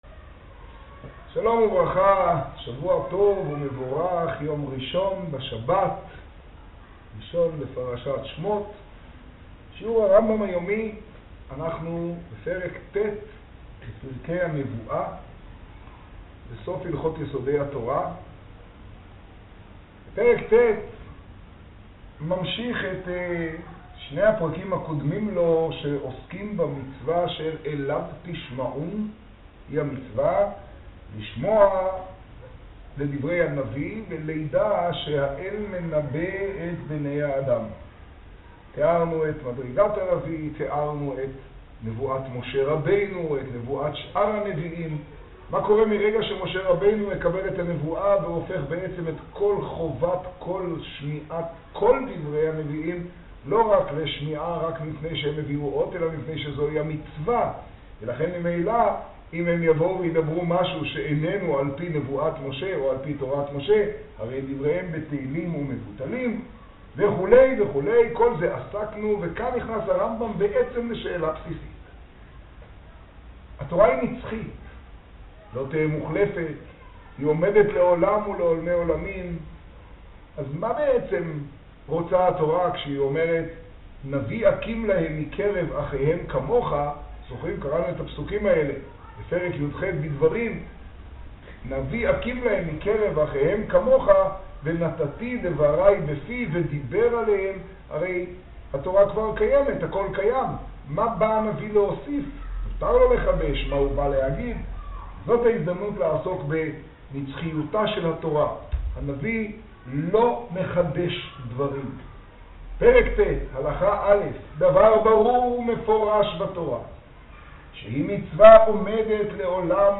השיעור במגדל, יג טבת תשעה.